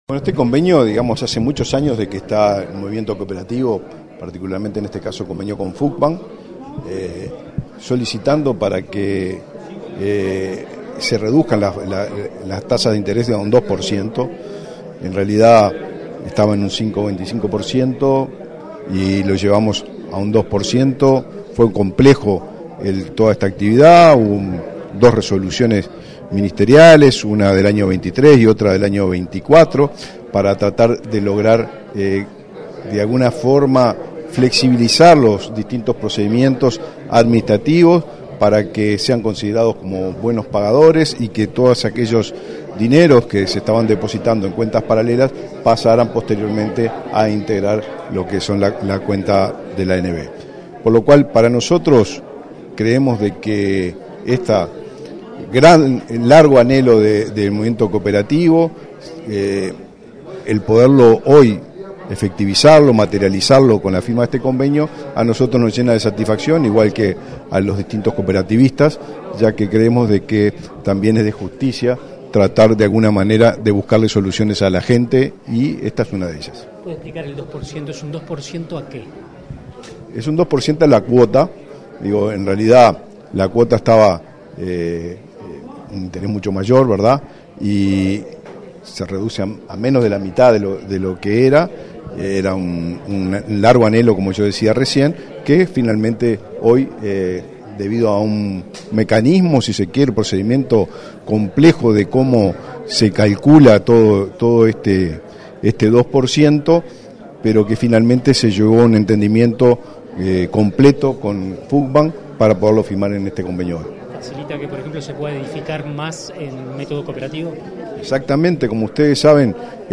Declaraciones a la prensa del titular del MVOT, Raúl Lozano
Declaraciones a la prensa del titular del MVOT, Raúl Lozano 11/02/2025 Compartir Facebook X Copiar enlace WhatsApp LinkedIn El Ministerio de Vivienda y Ordenamiento Territorial (MVOT) firmó, este 11 de febrero, un convenio con la Federación Uruguaya de Cooperativas de Vivienda por Ayuda Mutua (Fucvam), que permitirá reducir la tasa de préstamos al 2% anual. Luego, el titular de la cartera, Raúl Lozano, realizó declaraciones a la prensa.